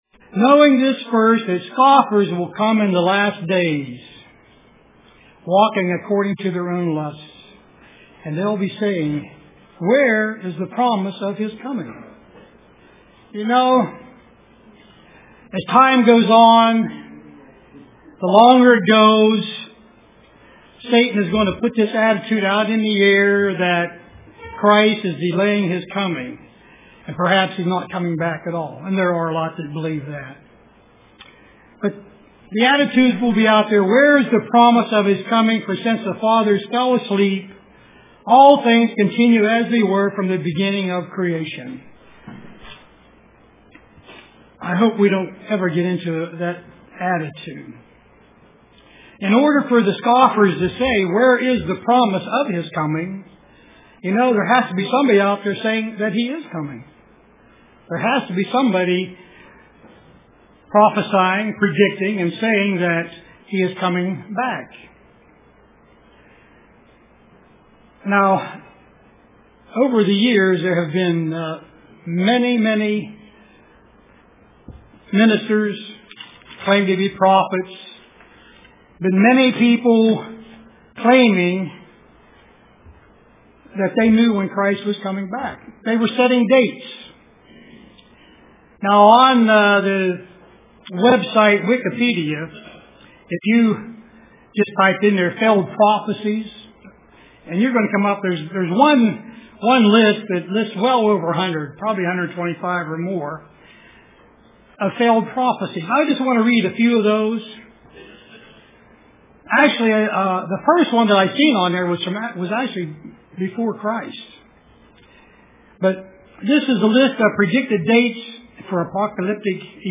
Print Her Place UCG Sermon Studying the bible?